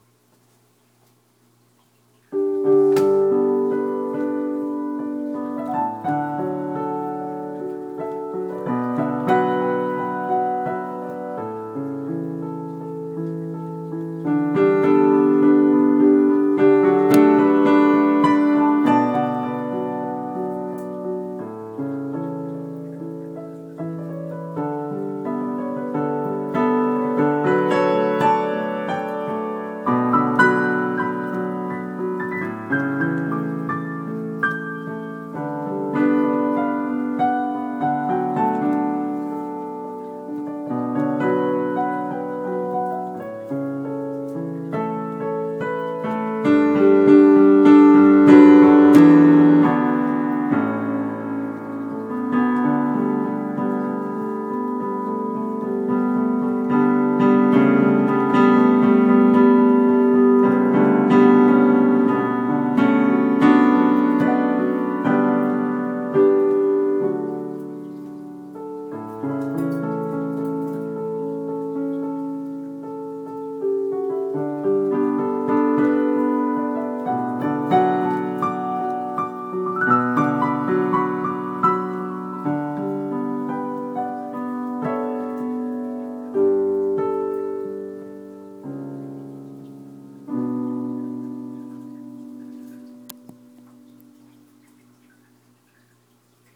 my fingers play}